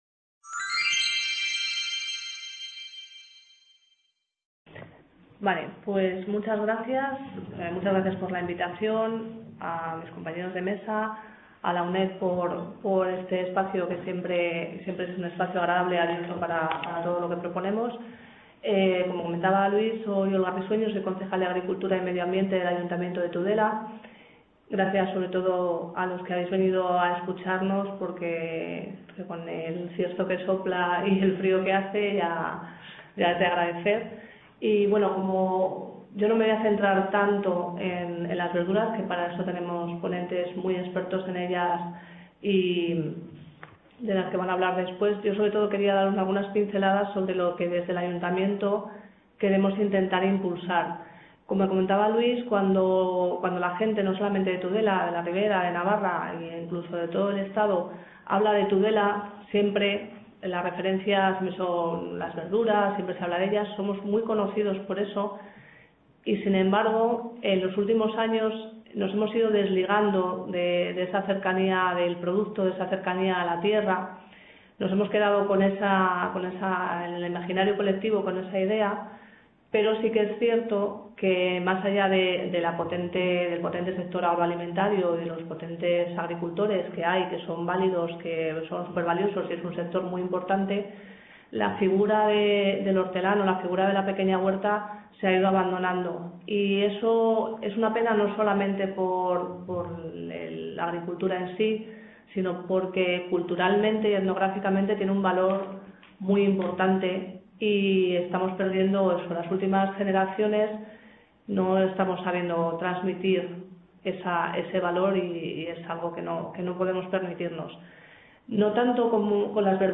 Mesa redonda